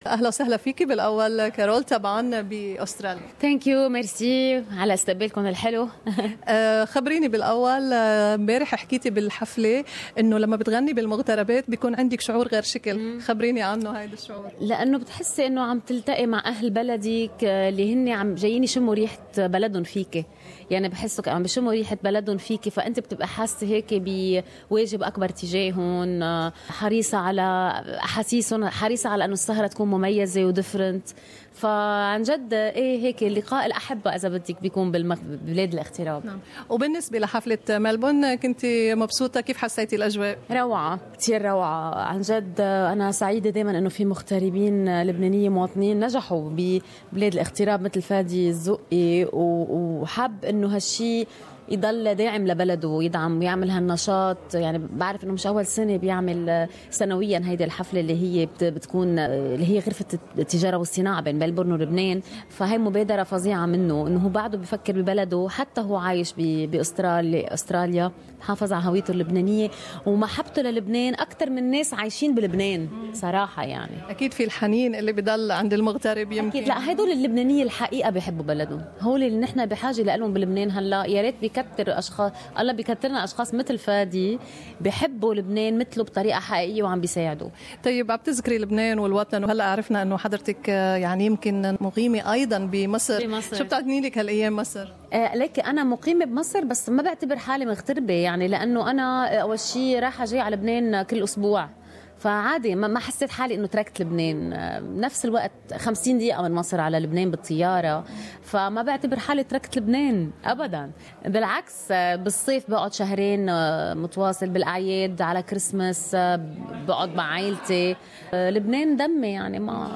Interview with Lebanese super star Carole Samaha